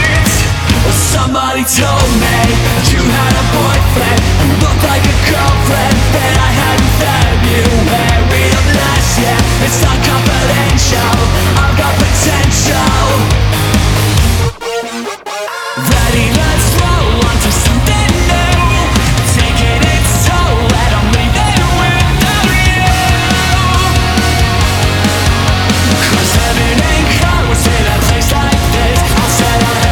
• Metal